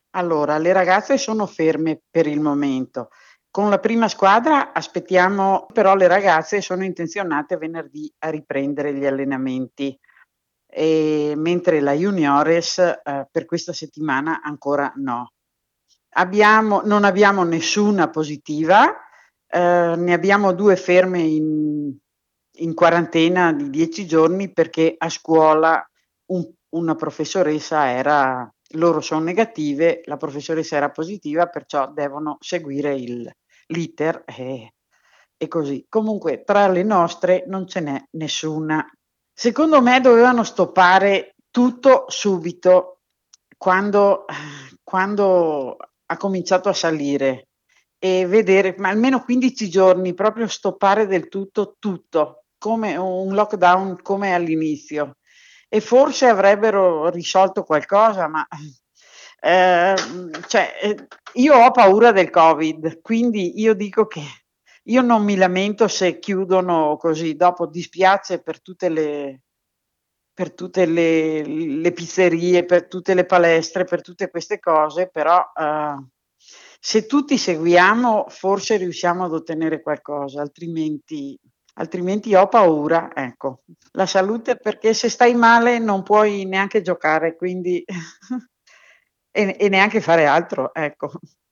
AI MICROFONI DI RADIO PIU’